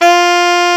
Index of /90_sSampleCDs/Roland LCDP07 Super Sax/SAX_Alto Short/SAX_A.ff 414 Sh
SAX A.FF F0A.wav